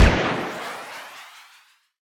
rocket_family.wav